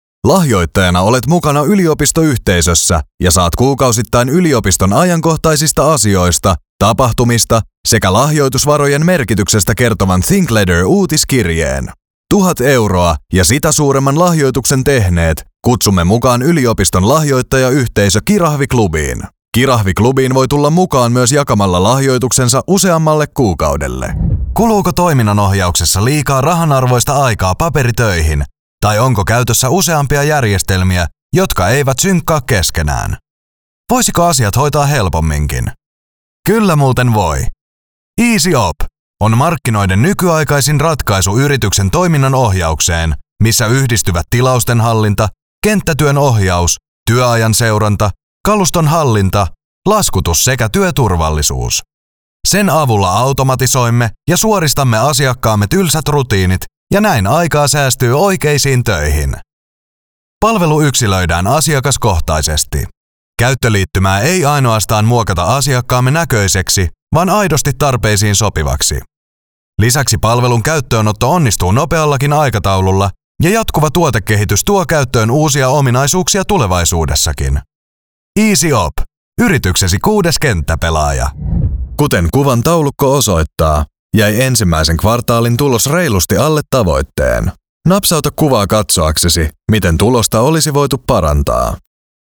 Junge, Cool, Corporate
Erklärvideo